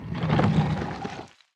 StatueOpen.wav